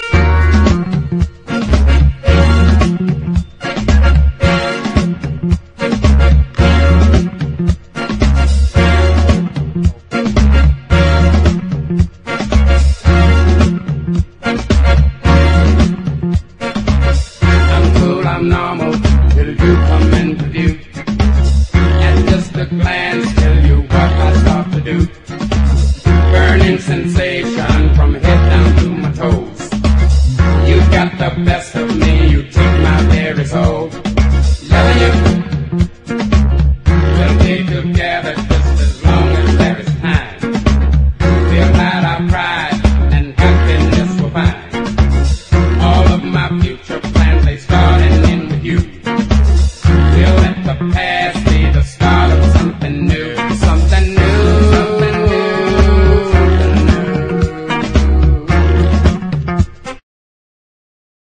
SOUL / SOUL / 60'S / RHYTHM & BLUES / MOD / POPCORN
MOD リズム&ブルース・クラシック満載！ ノース・カロライナ州グリーンズボロ出身の最強兄妹R&Bデュオ！
ソウルフルな擦れ声に心底惚れ惚れします。